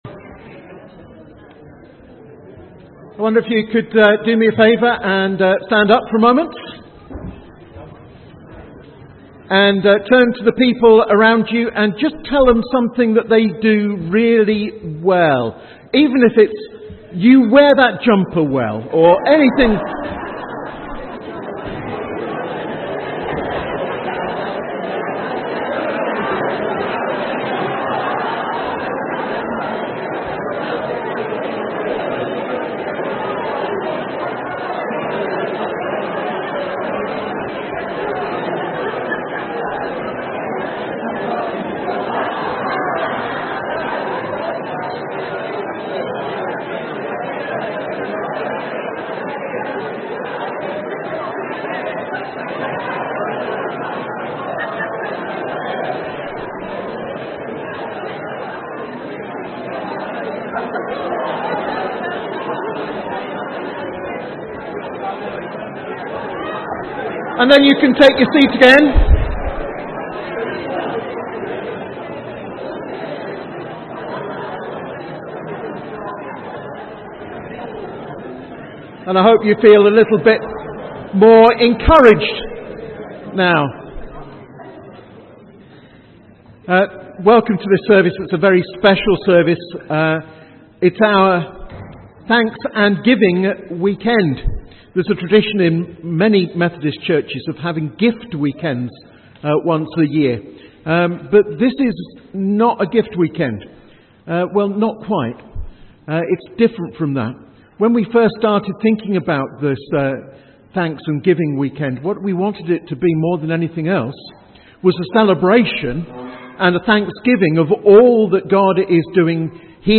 A message from the series
From Service: "10.45am Service"